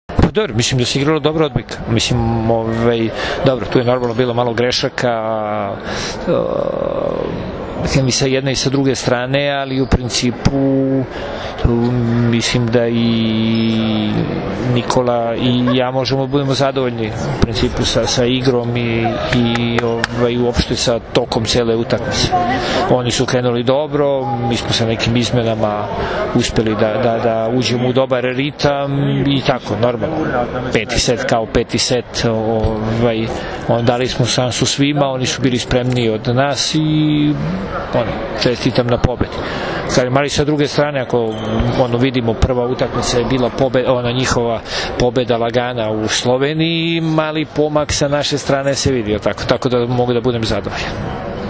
IZJAVA SLOBODANA KOVAČA